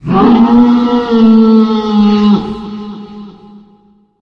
sounds / monsters / lurker / howl_1.ogg
howl_1.ogg